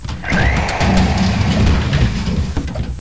debris.wav